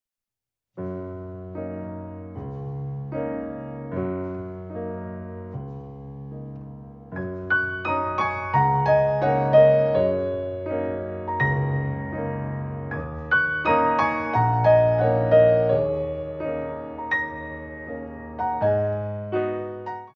Ballet class music for first years of ballet